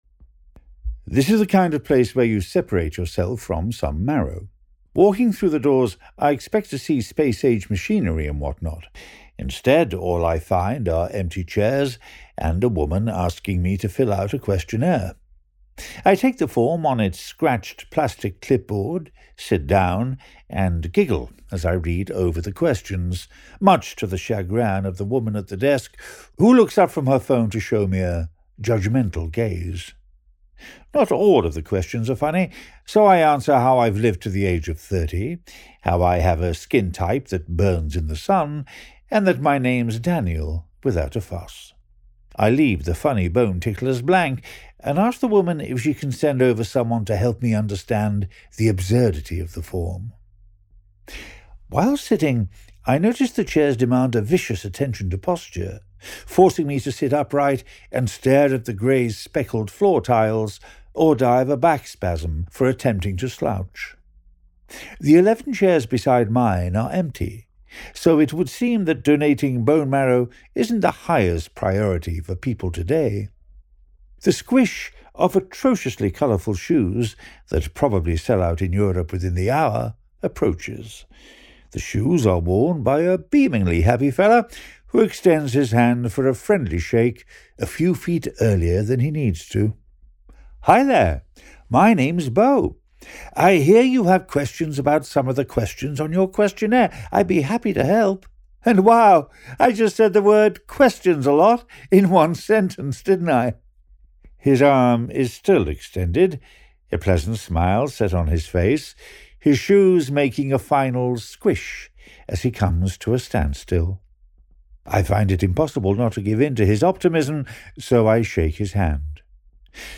british, male